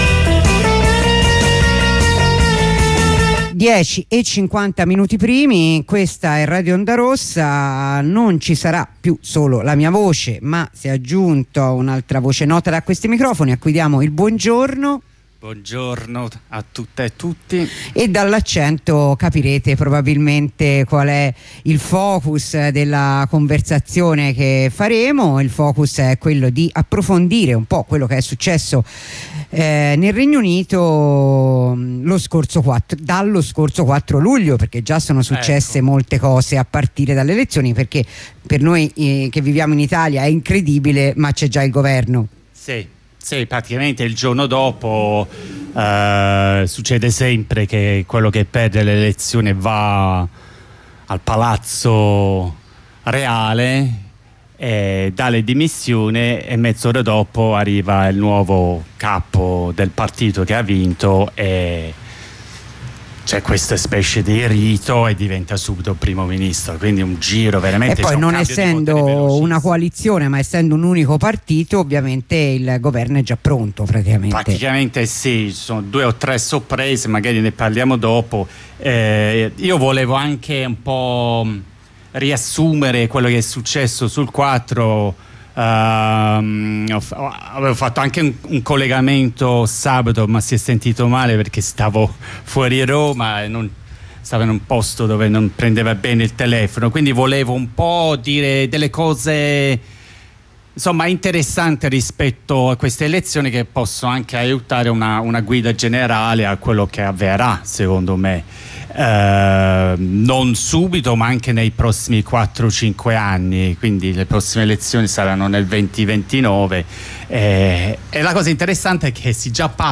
Corrispondenza dalla piazza di Cagliari